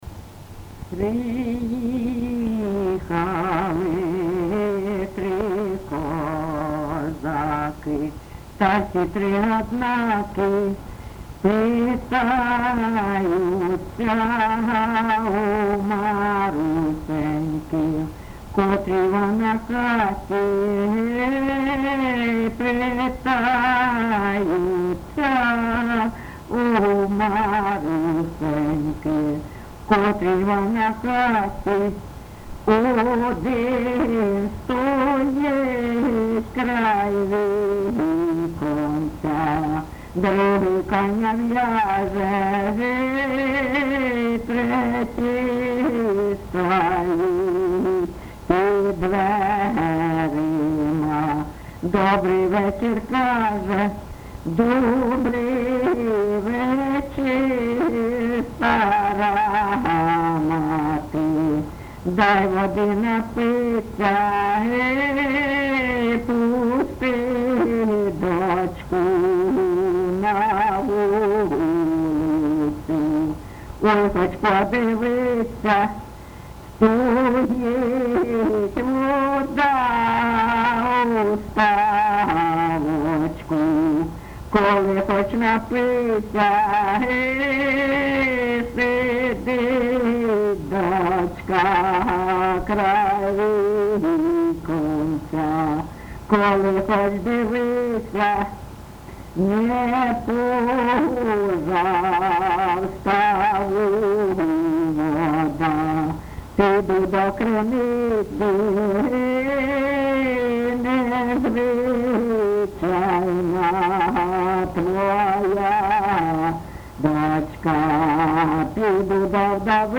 ЖанрПісні з особистого та родинного життя, Козацькі